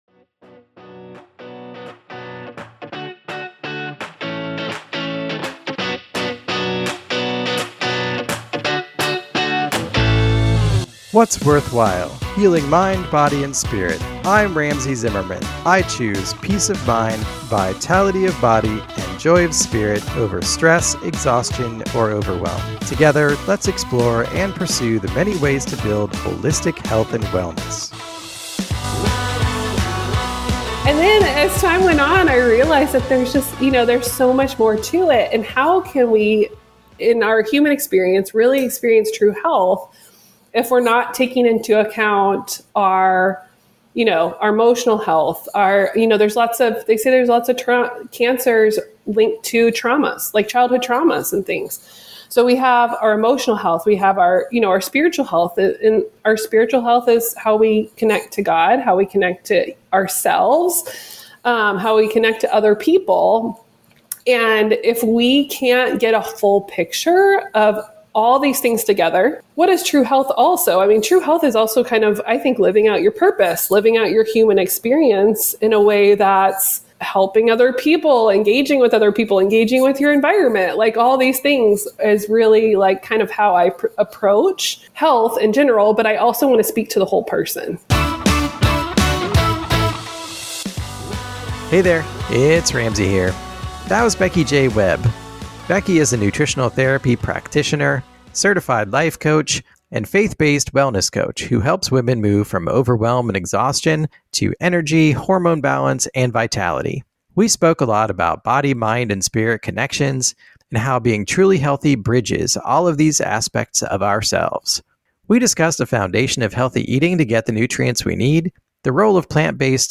This is an honest and open conversation, and a great resource for those who wish to nourish and balance not only t